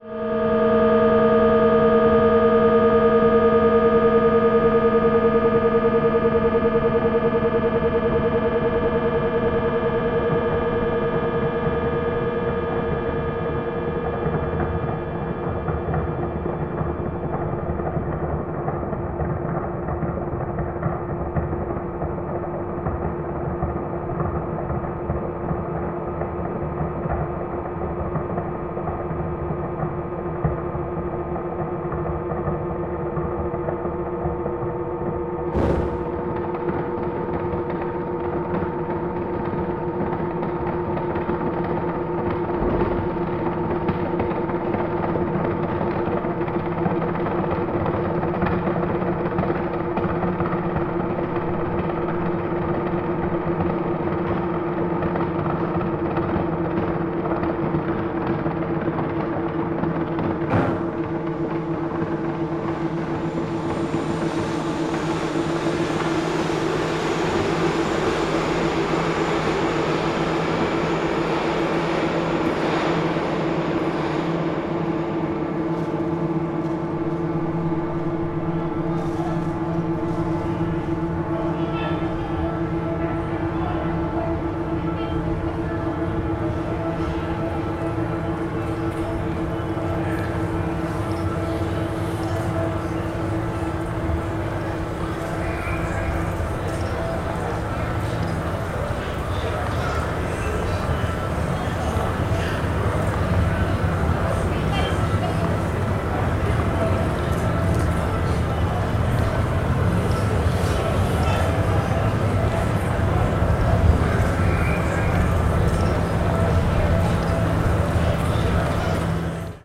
piano
voice
trpt
tuba